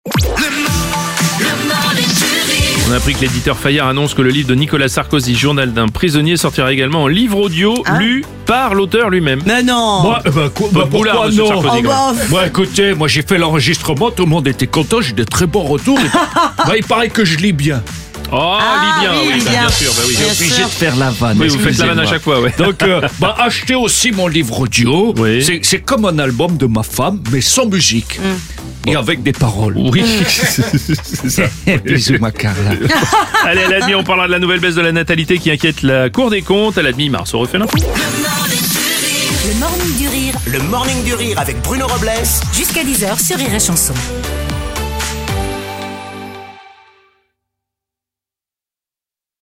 L’imitateur
en direct à 7h30, 8h30, et 9h30.